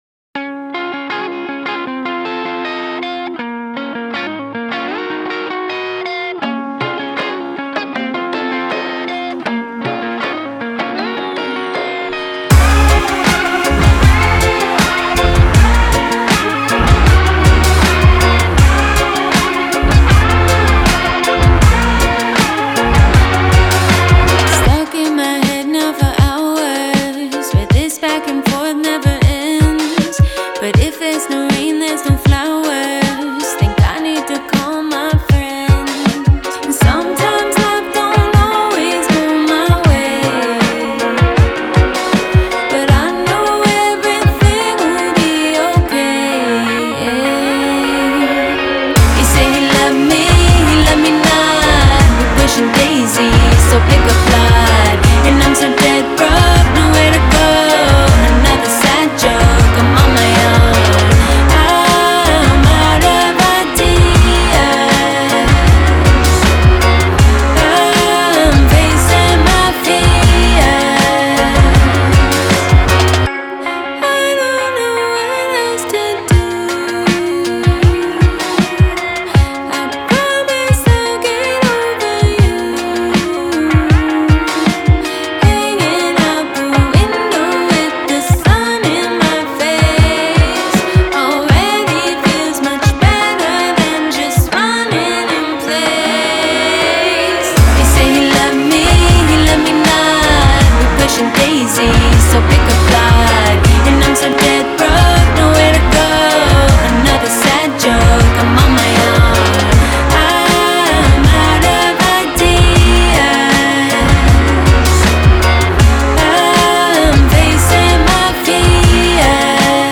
Los Angeles surf-pop trio